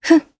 Isolet-Vox_Jump_jp.wav